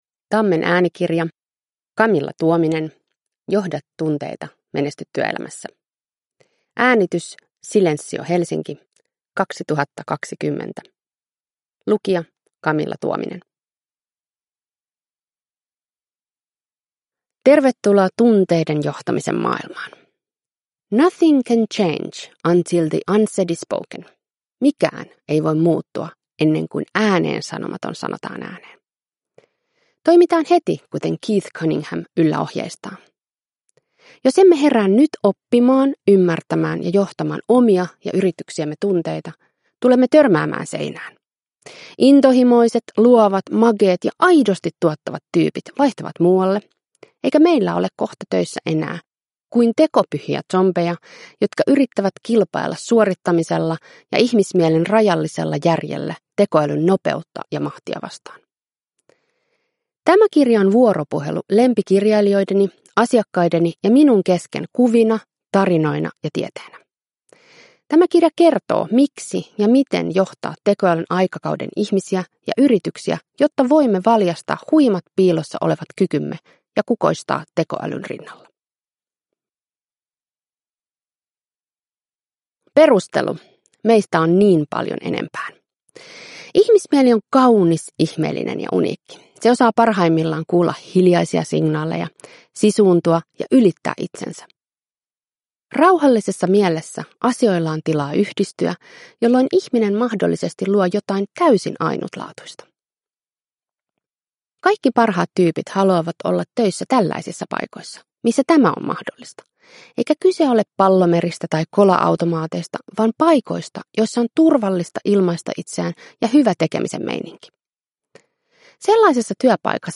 Johda tunteita - menesty työelämässä – Ljudbok – Laddas ner